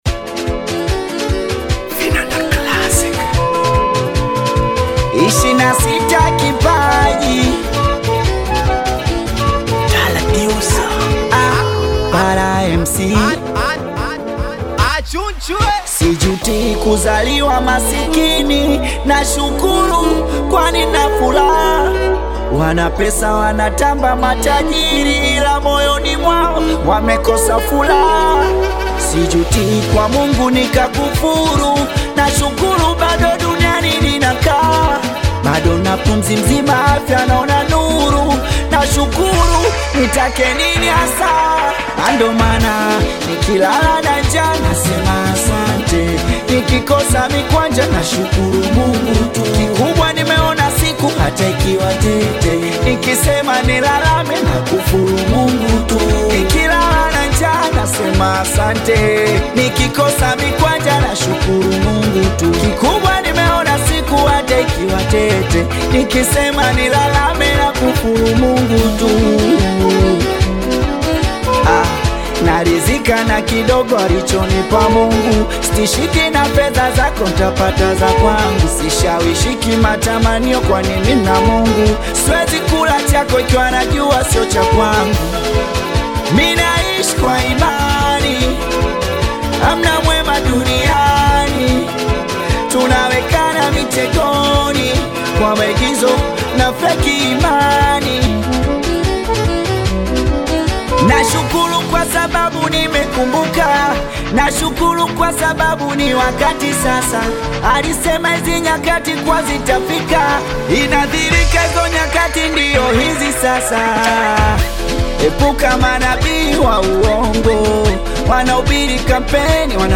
Singeli music track
Tanzanian Bongo Flava singeli artist, singer and songwriter